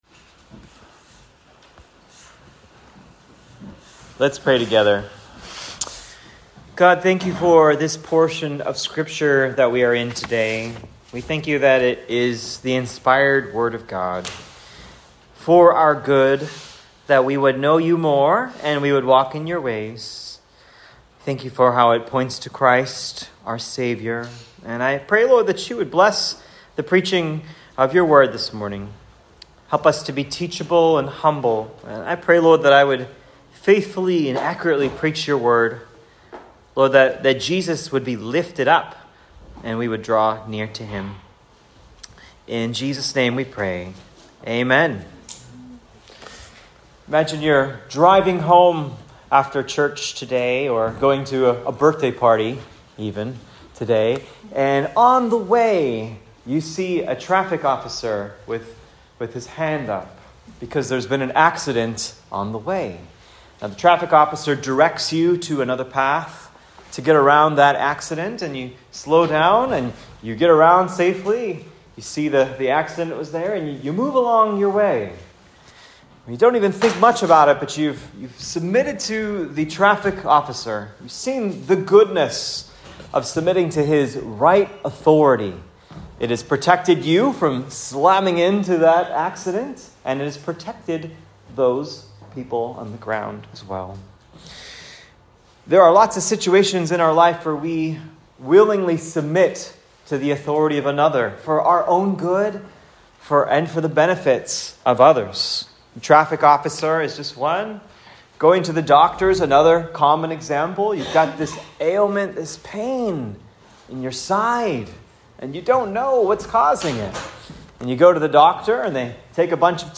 Ephesians 5:22-24 an instruction is given to Christian wives: submit to their own husbands, as the husband is the head of the wife. Sermon